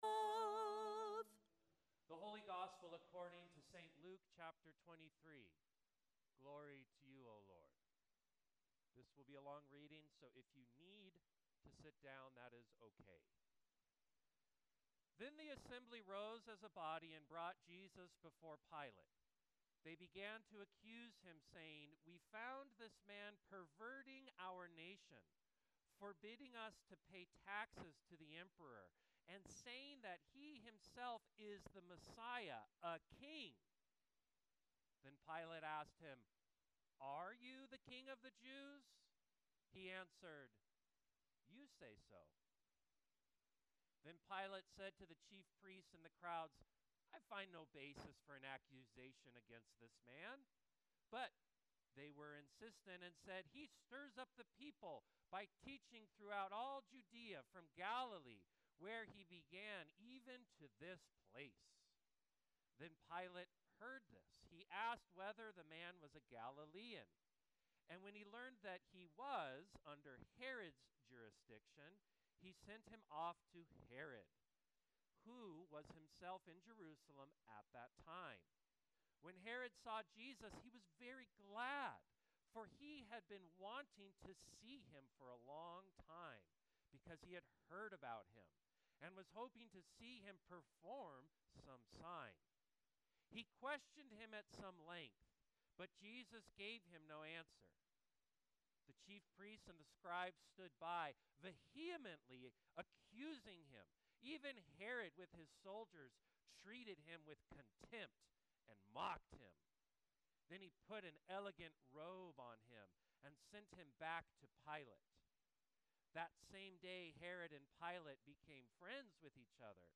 Sermon 04.13.25